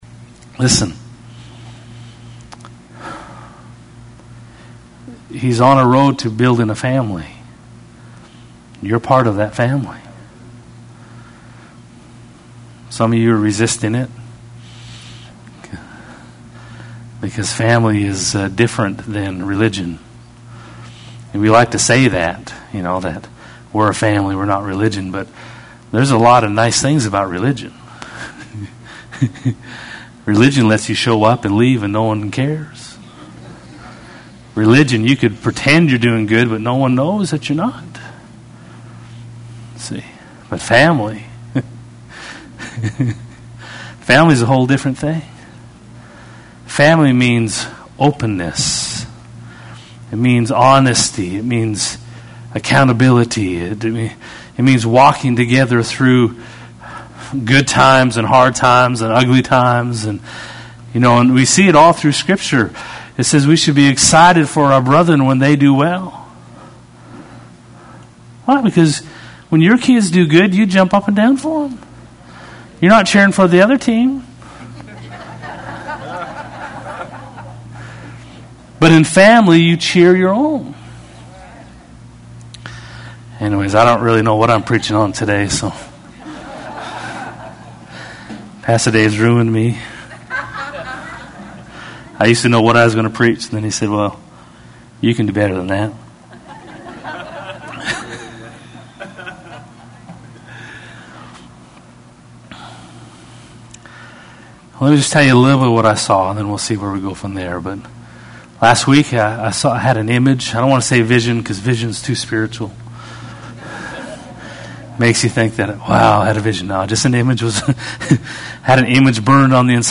Heard a great message and wanted to share my notes on some of it.